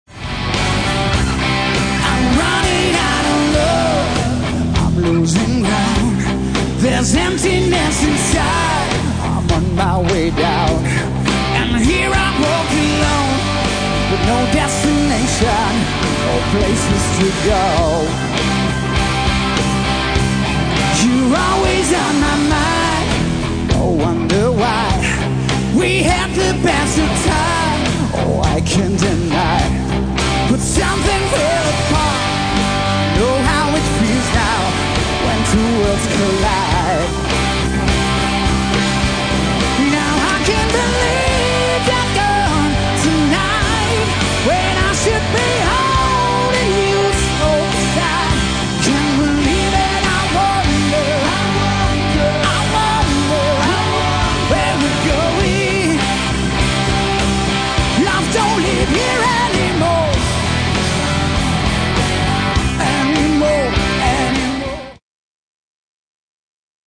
Place:Norje´